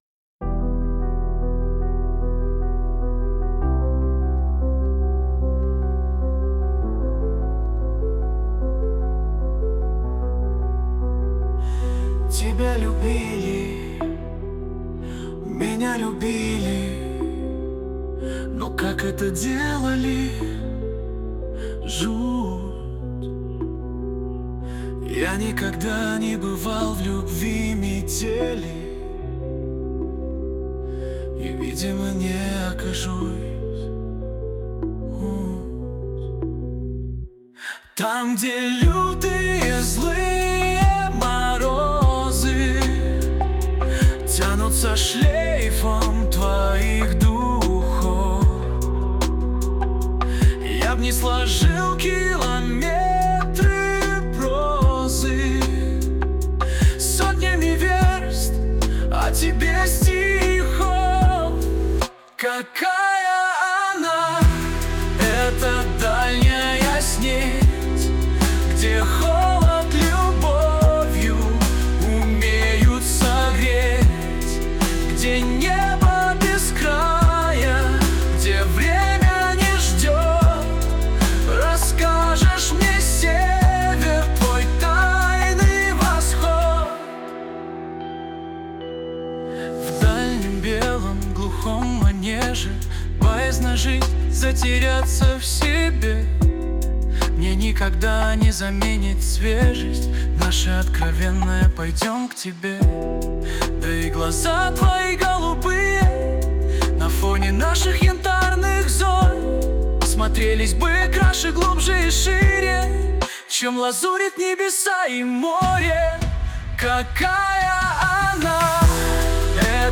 RUS, Romantic, Lyric, Pop | 16.03.2025 10:56